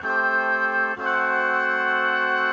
Index of /musicradar/gangster-sting-samples/95bpm Loops
GS_MuteHorn_95-A2.wav